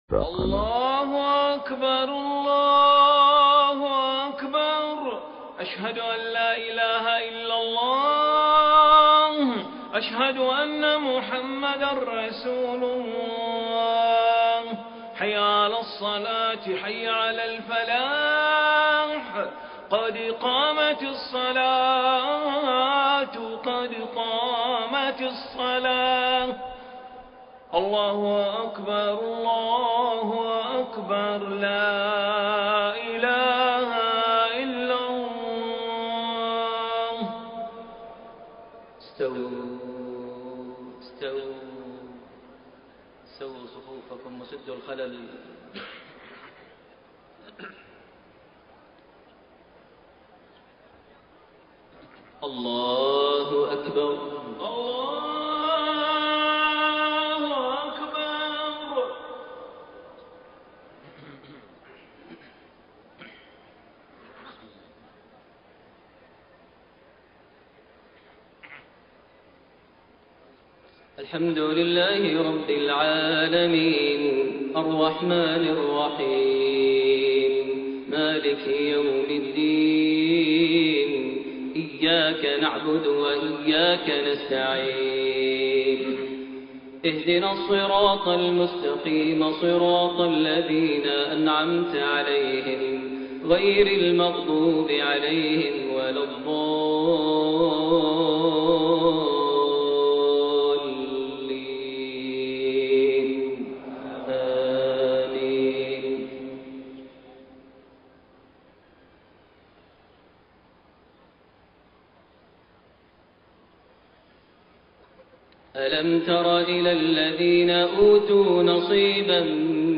صلاة العشاء 4-2-1433هـ من سورة آل عمران 23-32 > 1433 هـ > الفروض - تلاوات ماهر المعيقلي